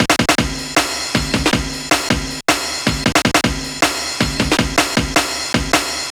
Airi Break 02-157.wav